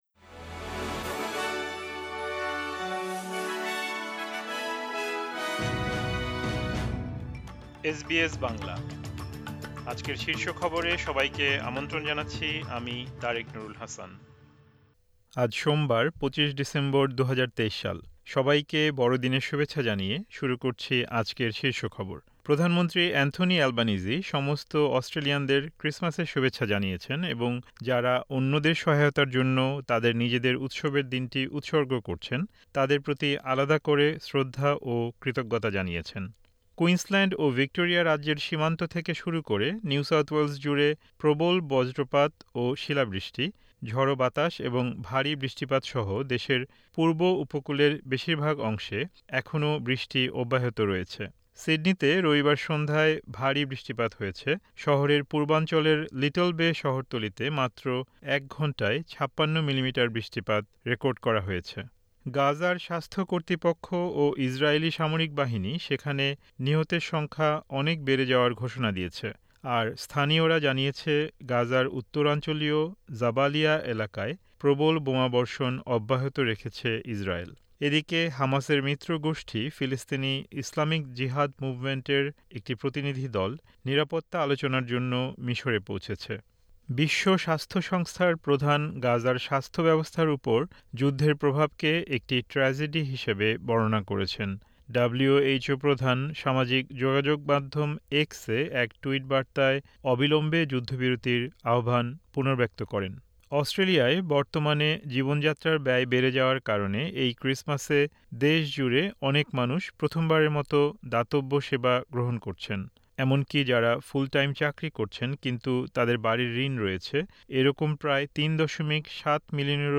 এসবিএস বাংলা শীর্ষ খবর: ২৫ ডিসেম্বর, ২০২৩